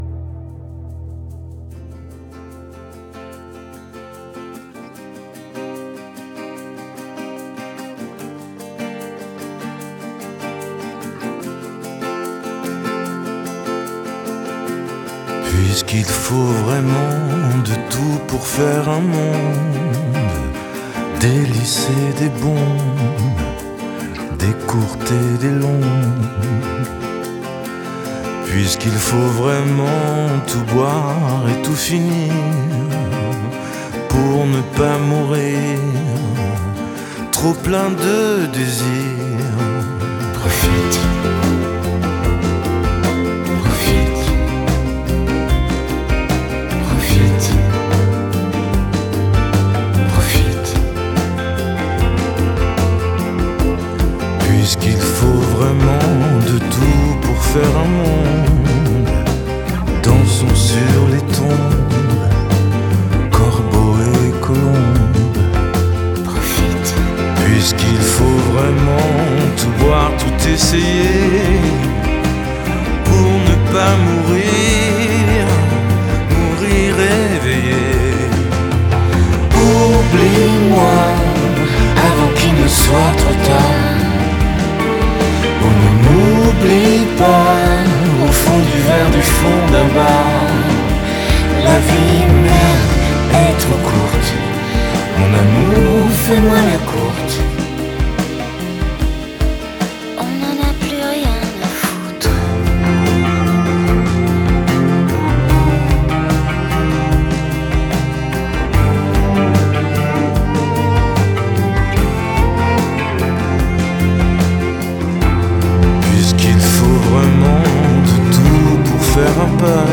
Genre: Pop, Chanson, French